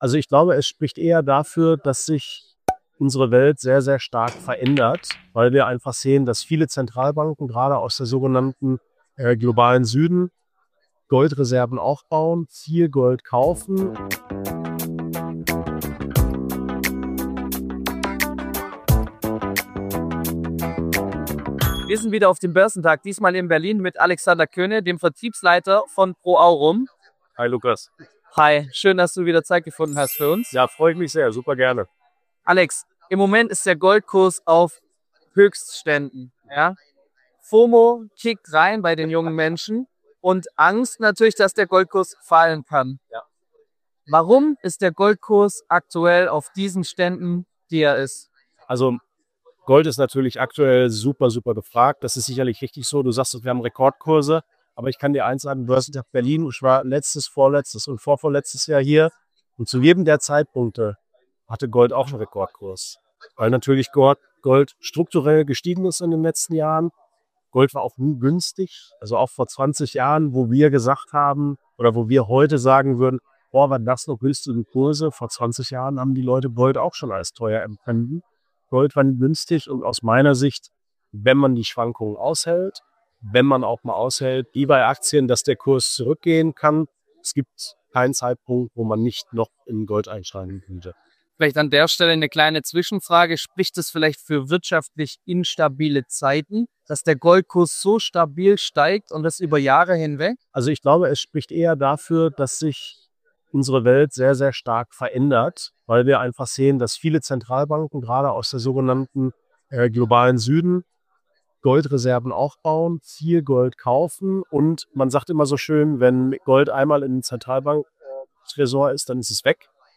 Kurzinterview